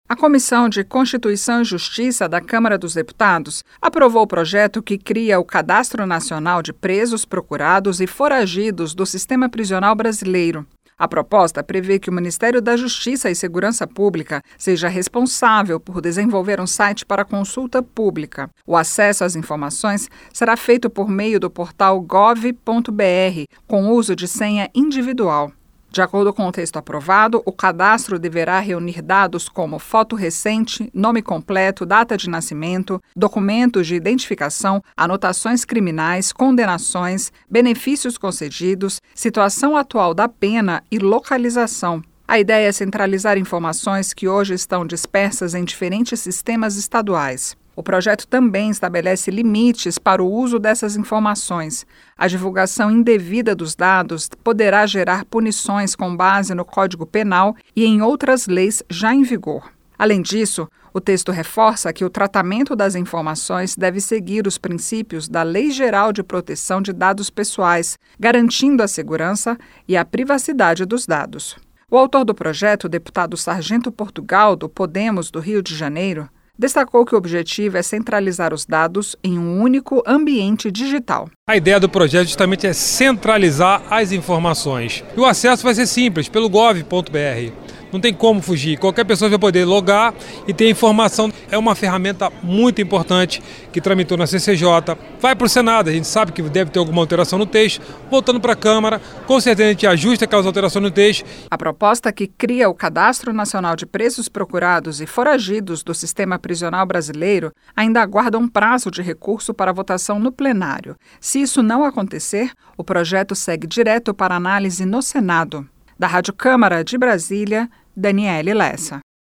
COMISSÃO APROVA PROJETO QUE CRIA CADASTRO NACIONAL DE PRESOS, APENADOS E FORAGIDOS, COM ACESSO PELA INTERNET. A REPÓRTER